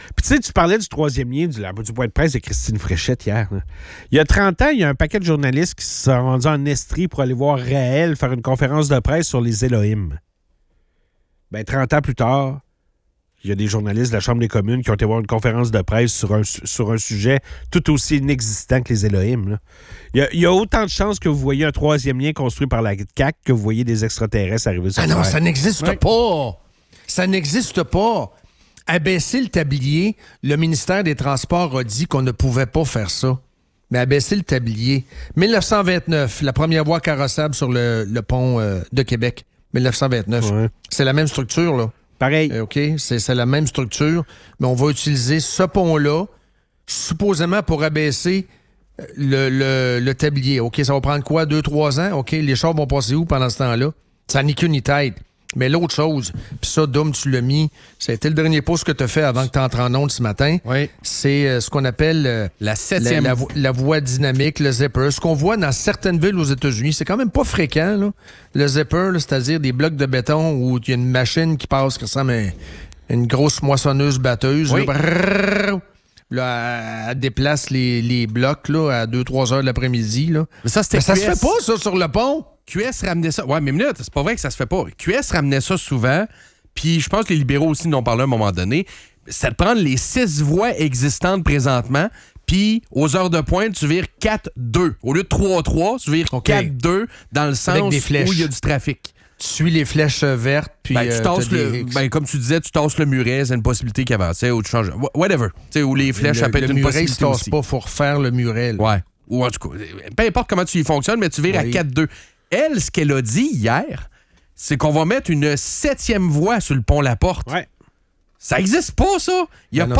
Le débat sur le troisième lien à Québec s’intensifie, avec des critiques acerbes sur les promesses de la CAQ et l’illusion d’un projet réalisable. Les animateurs évoquent les infrastructures vieillissantes, les priorités gouvernementales et les solutions potentielles pour désengorger la circulation.